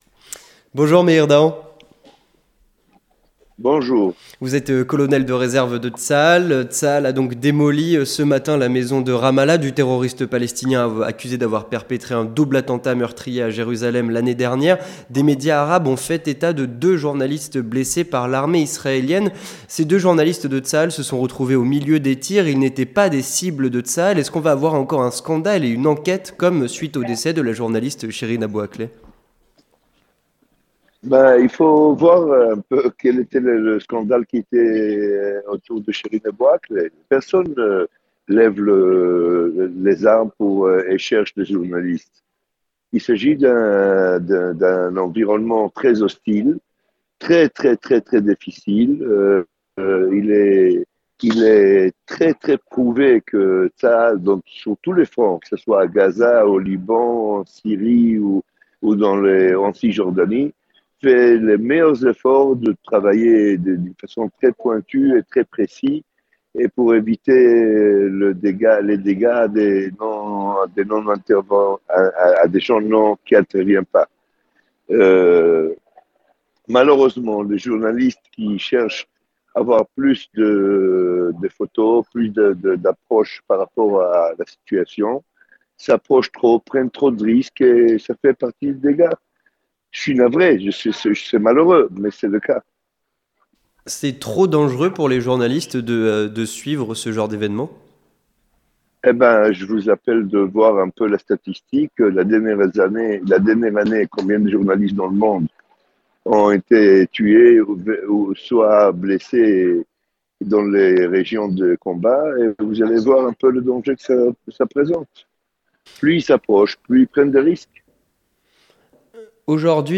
Entretien du 18h - Situation sécuritaire